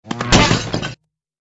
AA_drop_flowerpot_miss.ogg